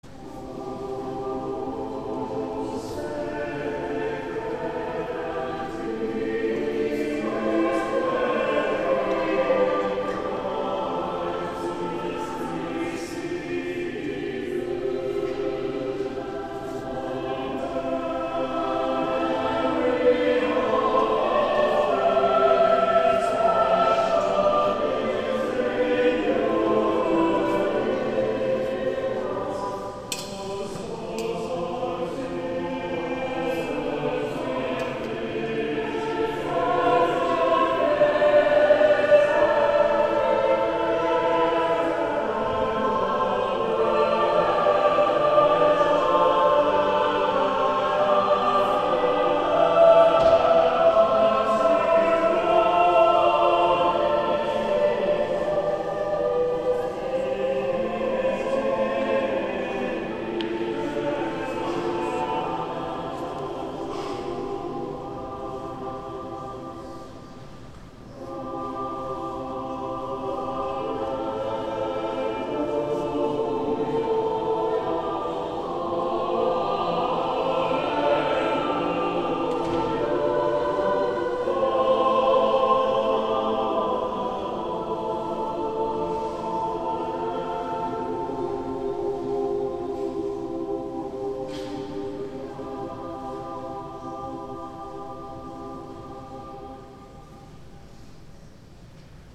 Communion - Healey Willan - O Sacred Feast